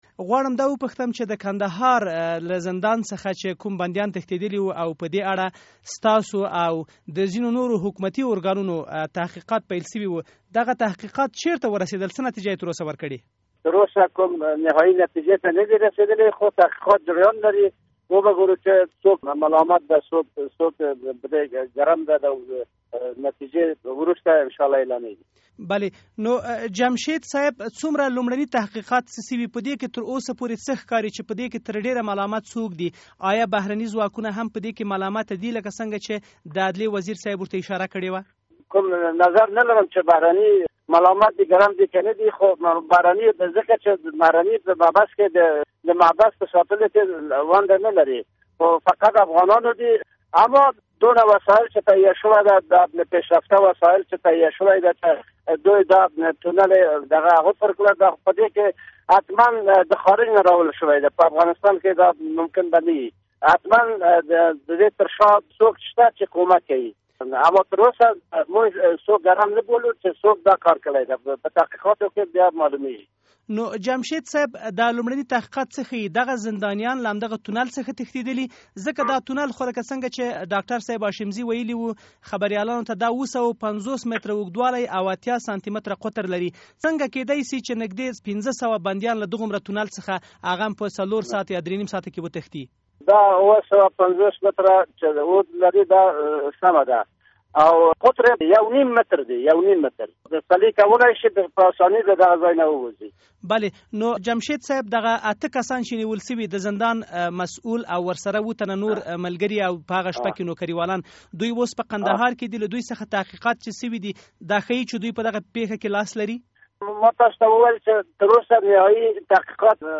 د عدلیې وزارت د محابسو له عمومي رییس جنرال امیرمحمد جمشید سره مرکه